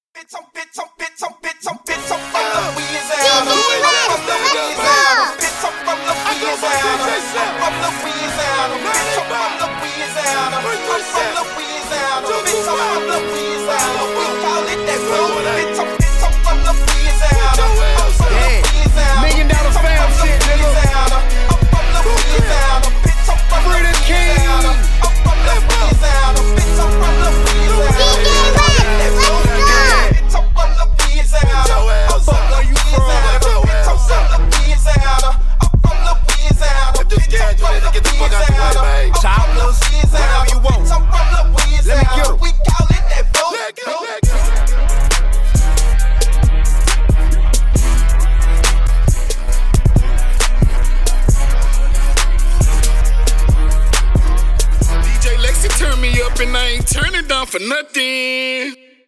HipHop
Louisiana Music